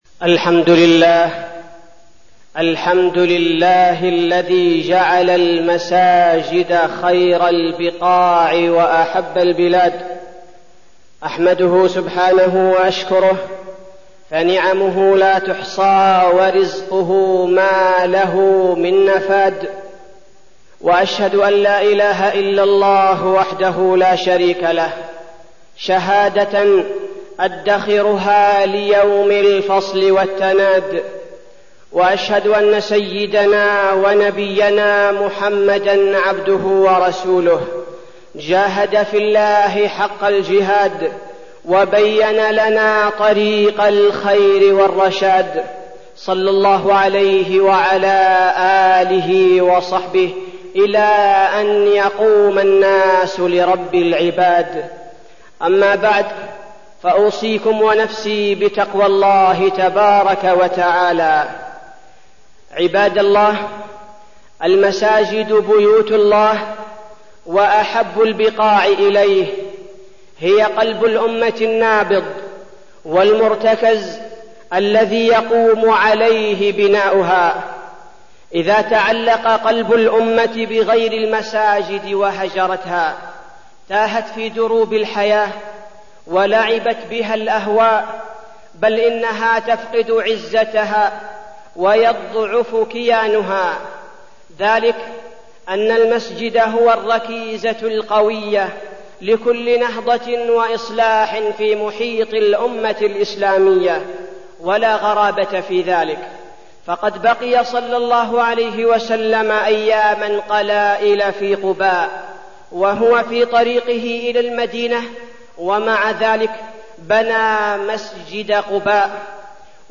تاريخ النشر ٢٢ شعبان ١٤١٩ هـ المكان: المسجد النبوي الشيخ: فضيلة الشيخ عبدالباري الثبيتي فضيلة الشيخ عبدالباري الثبيتي المساجد